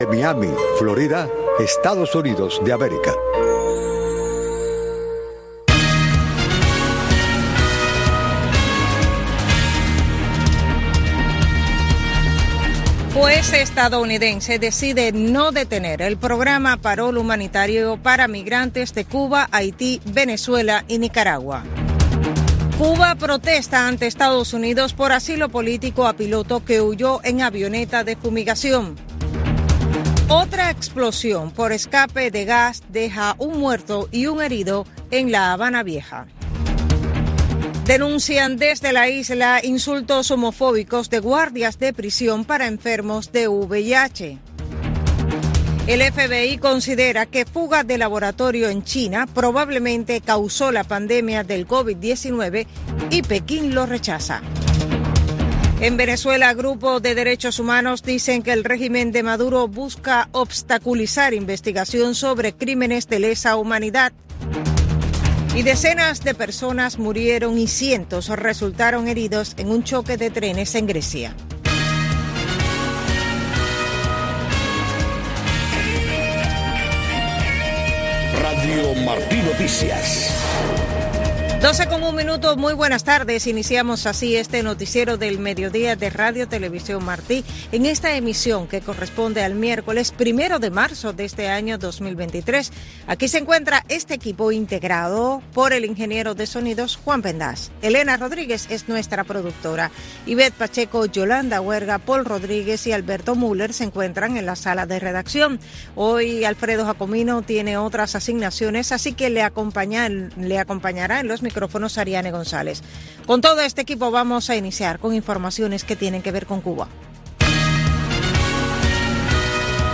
Noticiero Radio Martí presenta los hechos que hacen noticia en Cuba y el mundo.